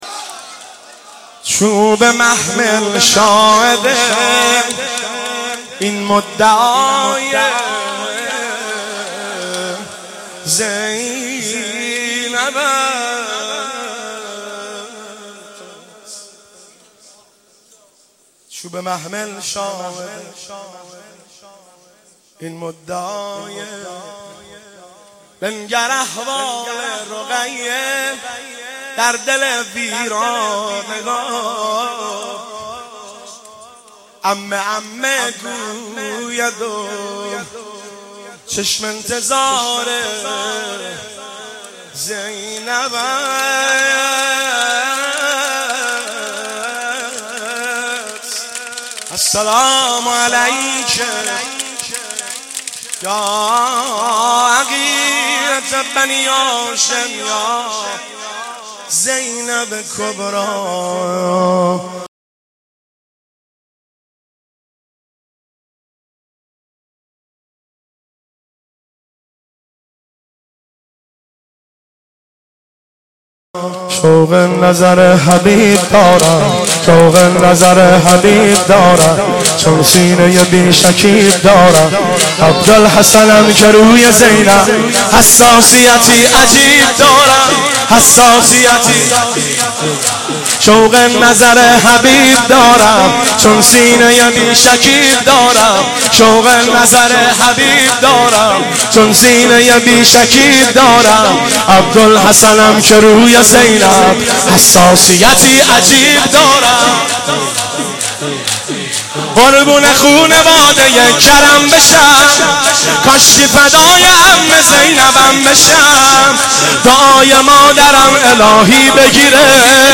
شب چهارم محرم95/هیئت غریب مدینه امیر کلا(بابل)
واحد/صدای سینه زن ها